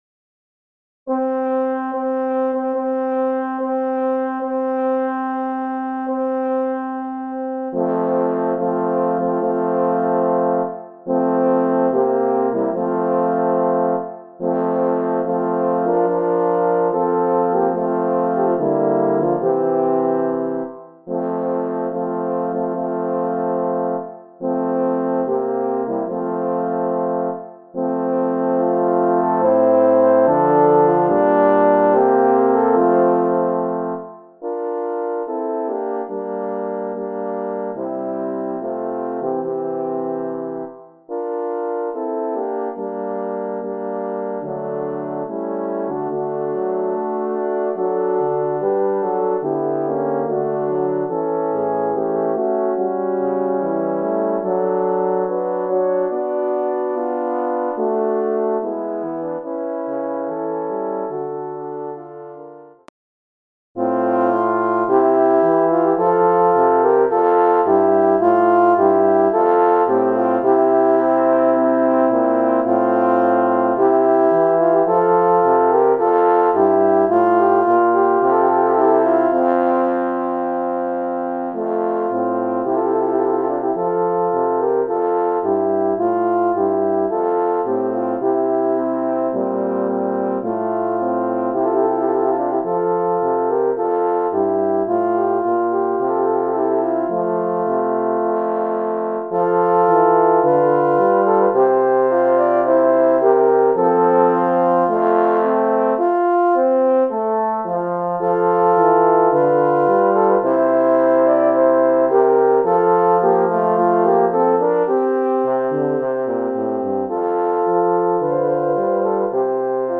Voicing: Horn Quartet